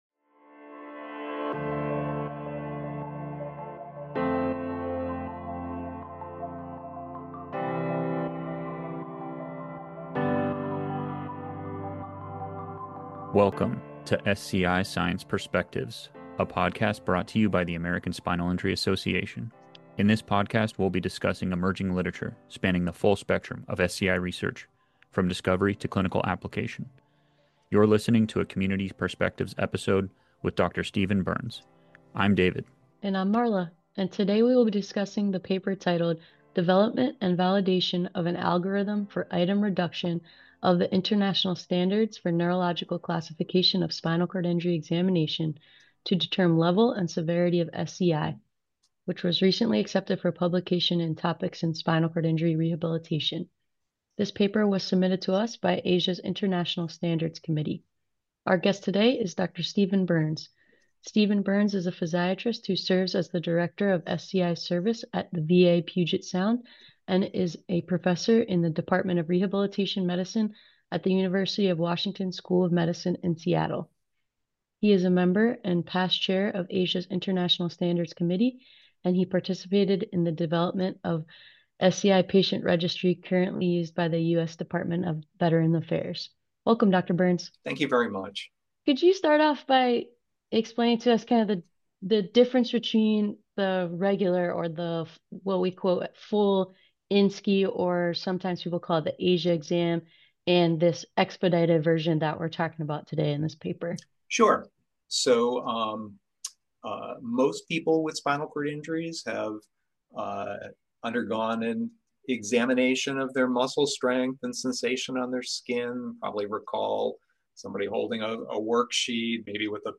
Then the podcast host(s) then interview the author(s) of the papers, approaching their project from each perspective.